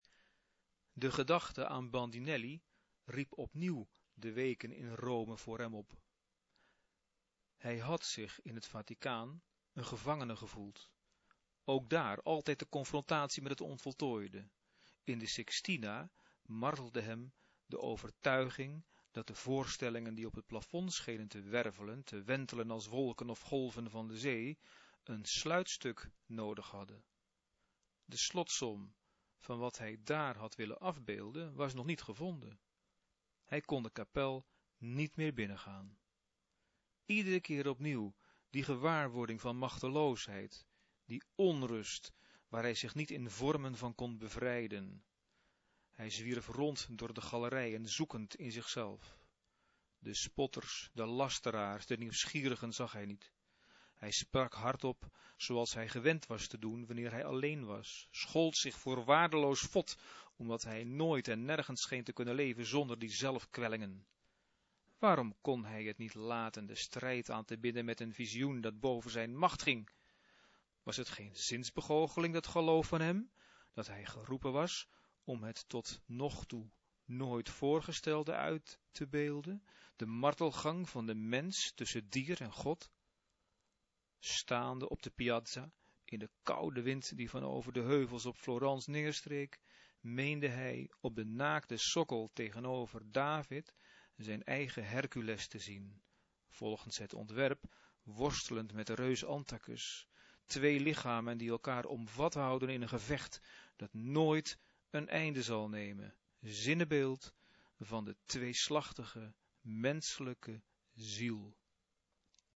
Betekent: het fragment wordt voorgelezen. (MP-3)